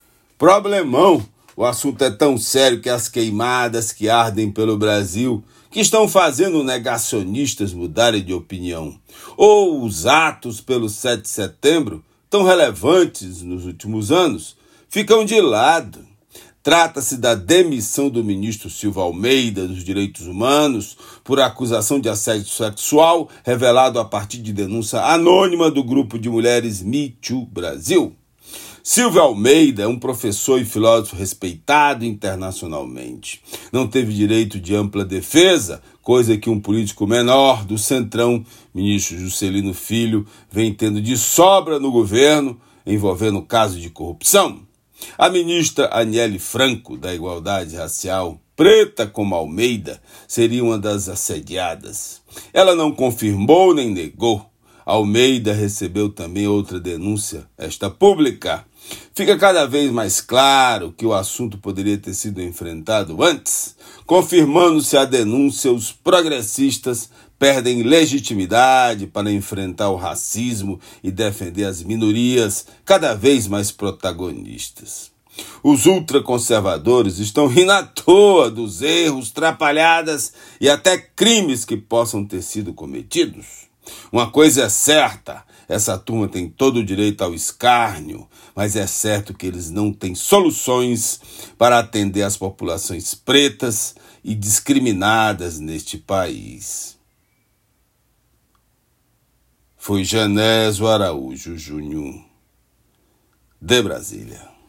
Comentário desta segunda-feira
direto de Brasília.